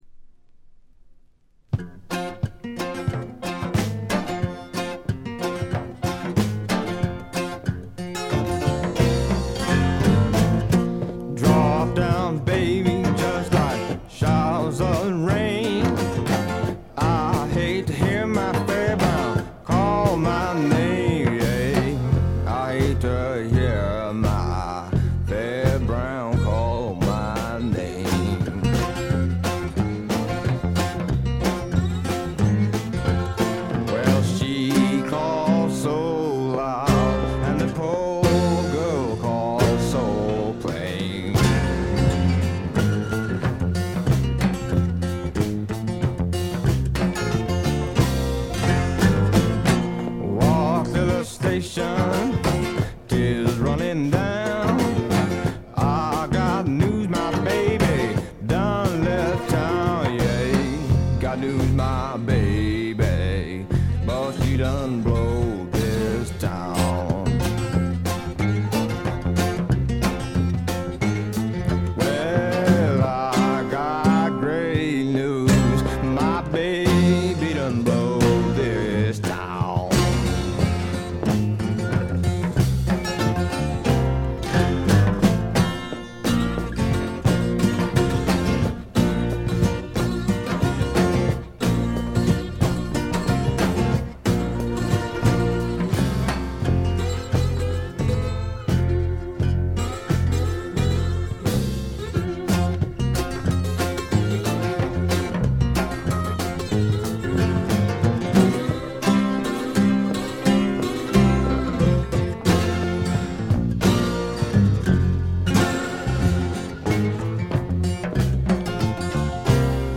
ほとんどノイズ感無し。
試聴曲は現品からの取り込み音源です。
Guitar, Mandolin, Bass